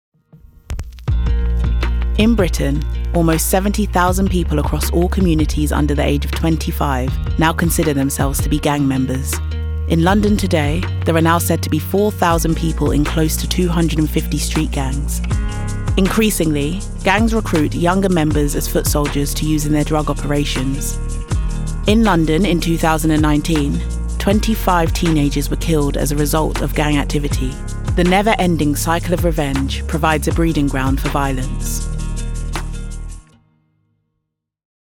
• Female
• London